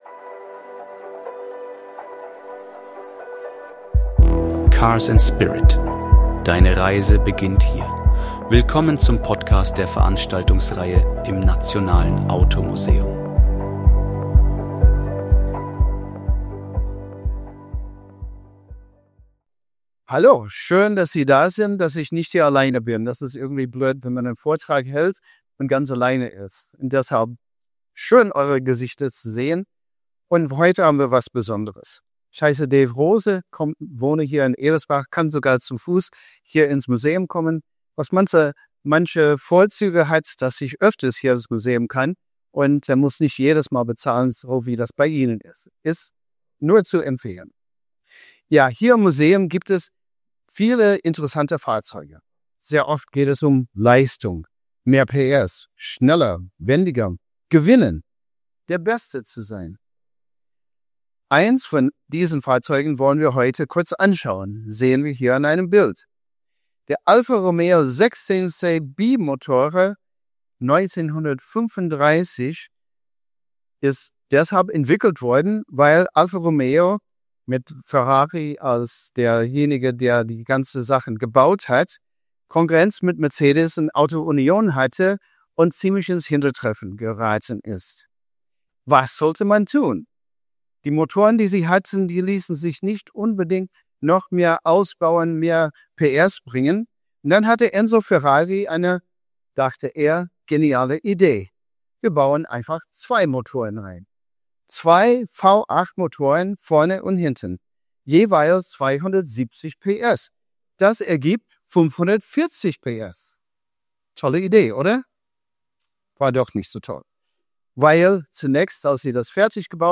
Die Veranstaltungsreihe im Nationalen Automuseum.